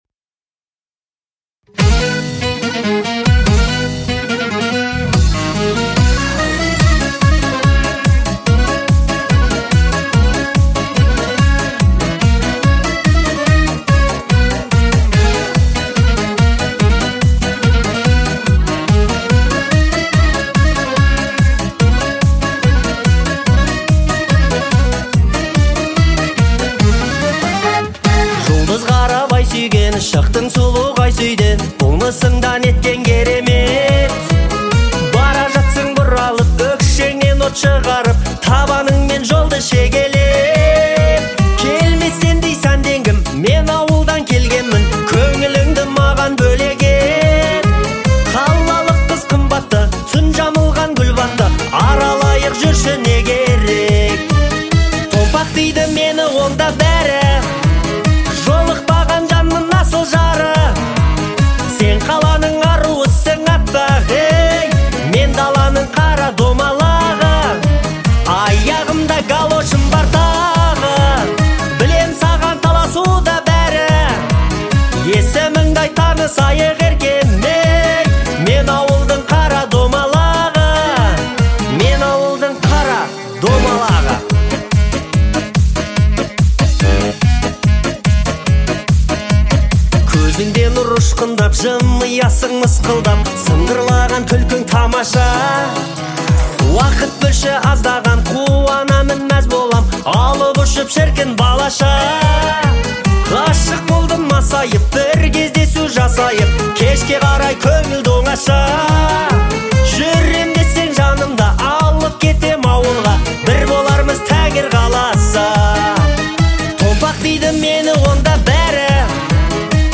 это яркая и мелодичная песня в жанре казахского фольклора